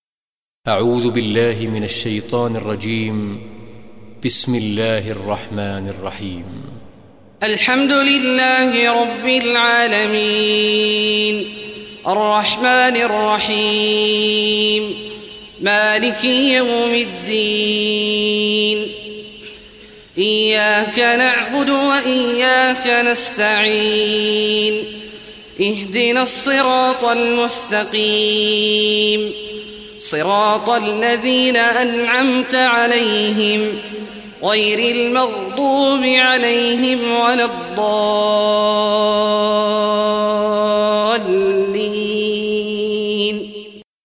Surah Fatiha Recitation by Sheikh Awad al Juhany
Surah Fatiha, listen or play online mp3 tilawat / recitation in Arabic in the beautiful voice of Sheikh Abdullah Awad Al Juhany.